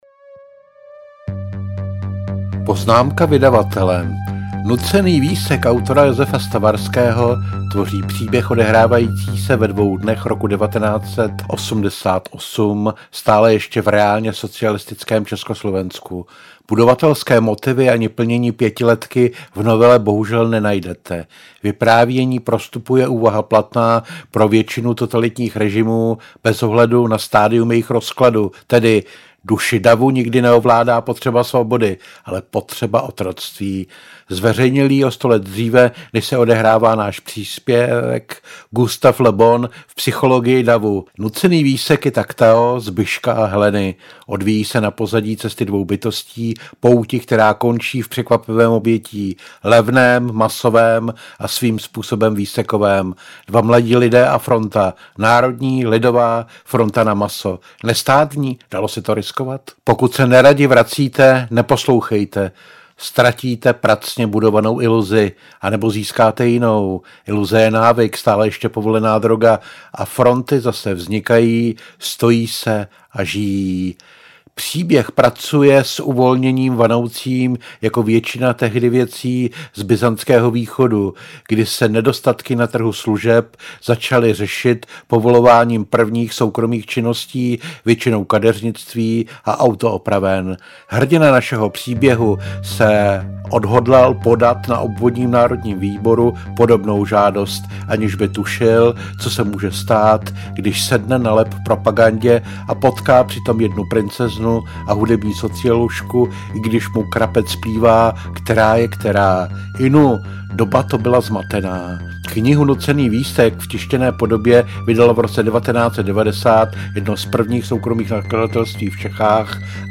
Nucený výsek audiokniha
Ukázka z knihy